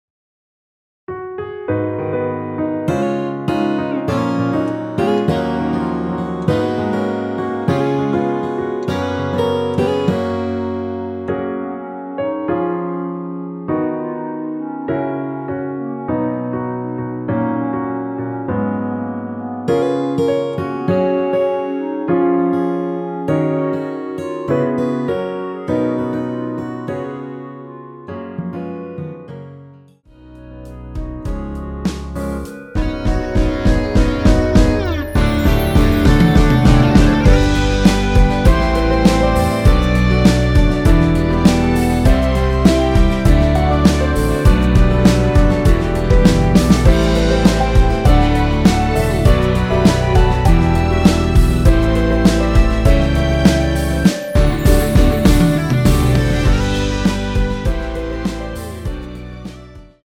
원키에서(-3)내린 멜로디 포함된 MR입니다.(미리듣기 확인)
Gb
앞부분30초, 뒷부분30초씩 편집해서 올려 드리고 있습니다.
중간에 음이 끈어지고 다시 나오는 이유는